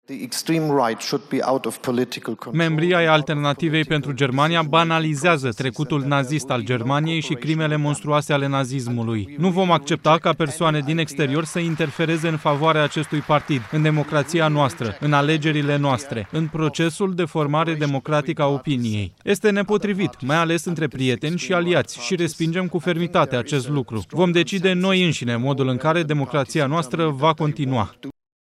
Scholz a declarat astăzi, la Conferința de Securitate de la Munchen, că mesajele transmise de vicepreședintele american sunt văzute ca un sprijin pentru partidul de extremă dreapta Alternativa pentru Germania.
Cancelarul german Olaf Scholz a subliniat, în discursul său,  că nu este corect ca alții să spună Germaniei și Europei ce să facă.
15feb-14-Scholz-replica-catre-Vance-TRADUS.mp3